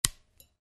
Звук треснувшего сердца (появилась трещина)